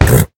horse_hit3.ogg